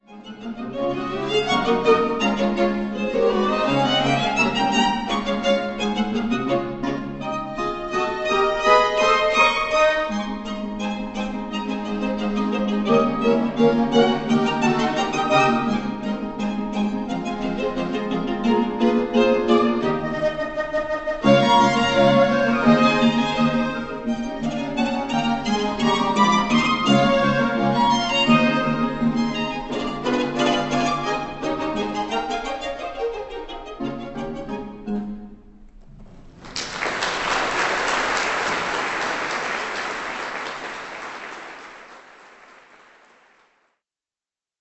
** Quartett mit Knopfharmonika
Aufgenommen live am 13.5.2007,